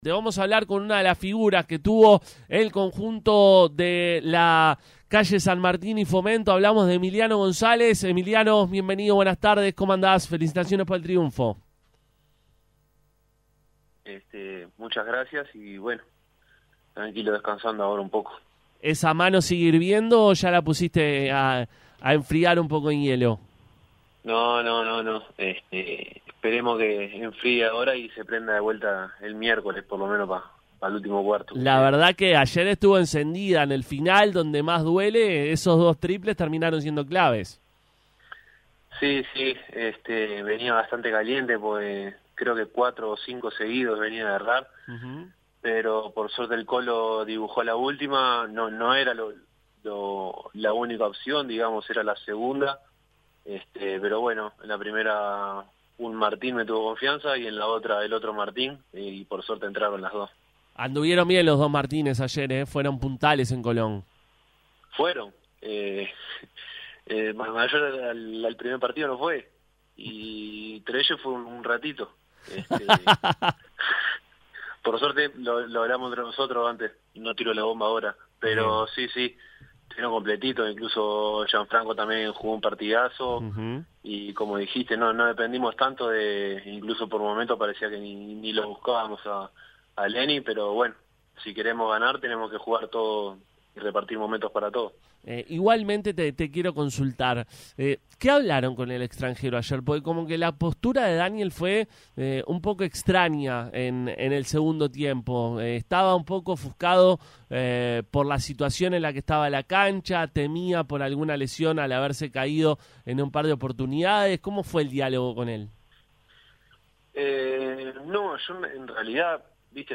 pasó por los micrófonos de Pica la Naranja tras el triunfo del equipo ante Tabaré.